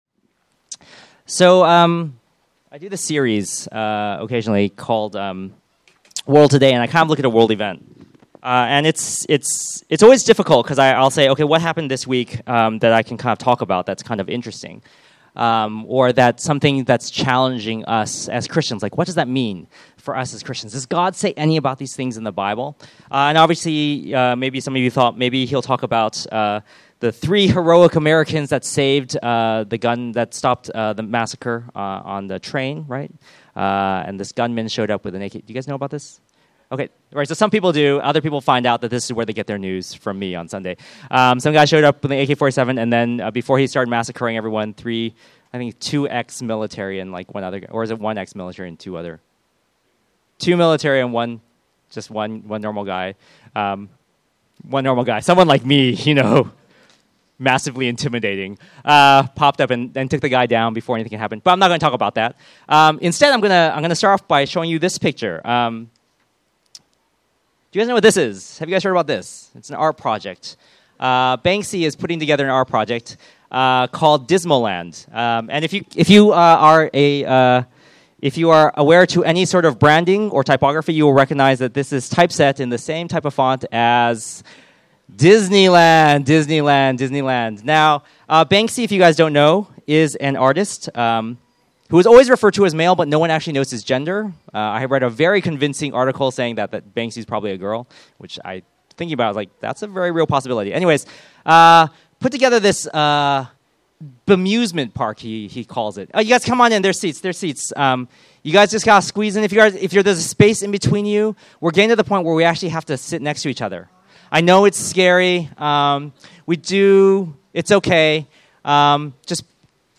Download Filename 150823Sermon.mp3 Filesize 18.51 MB Version 1 Date added 23 August 2015 Downloaded 1111 times Category Sermon Audio Tags hospitality